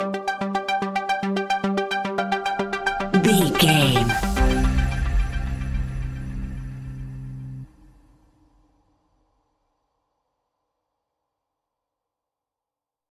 Dynamic Suspense Music Cue Stinger.
In-crescendo
Thriller
Aeolian/Minor
ominous
eerie
Horror Pads
horror piano
Horror Synths